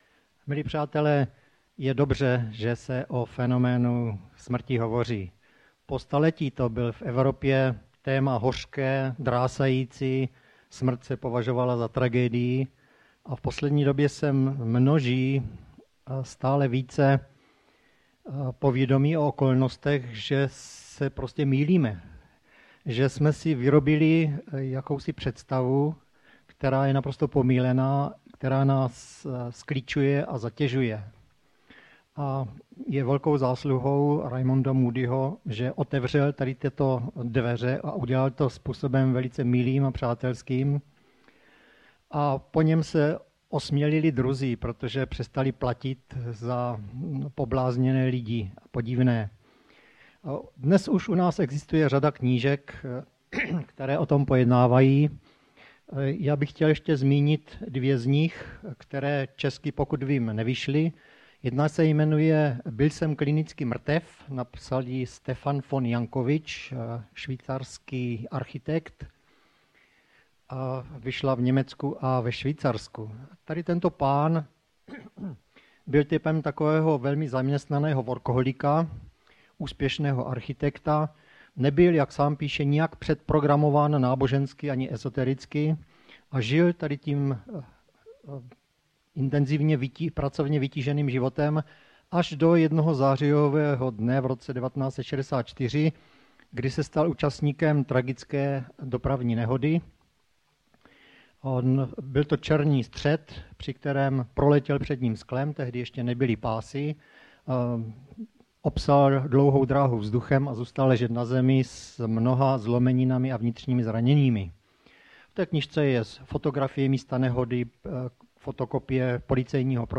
audioknižní záznam z přednášky